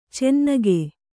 ♪ cennage